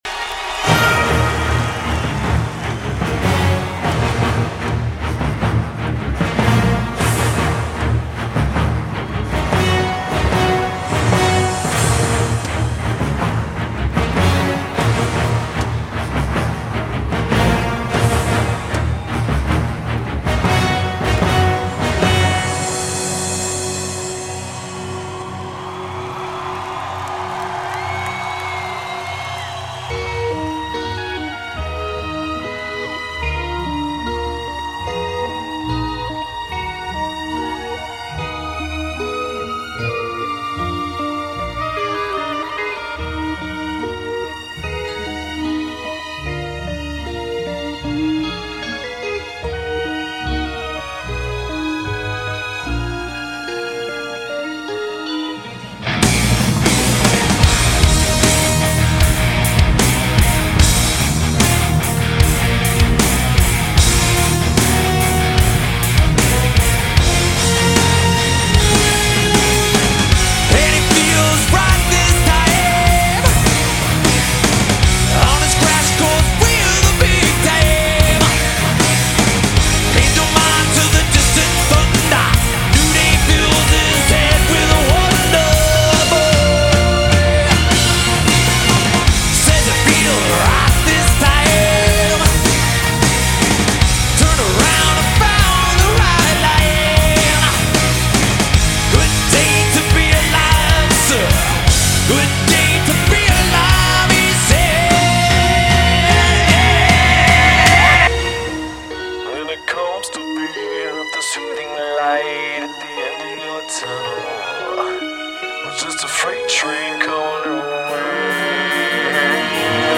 с симфоническим оркестром